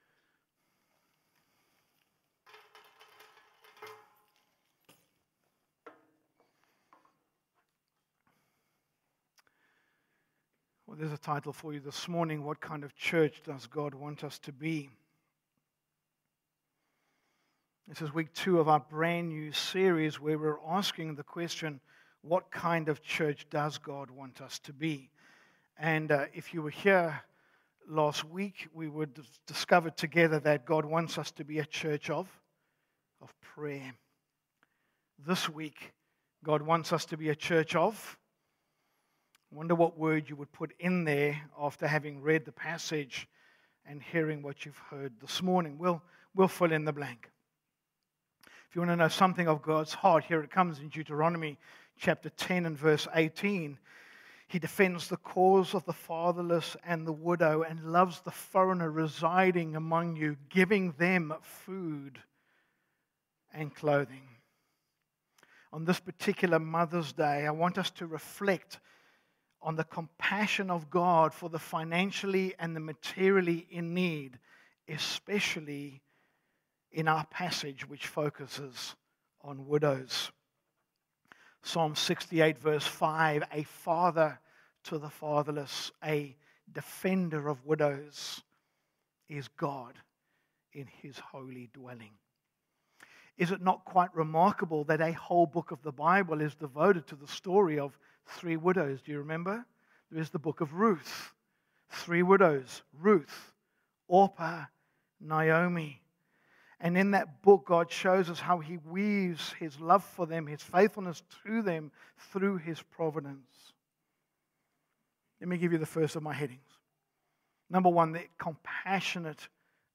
Sermons by Busselton Baptist Church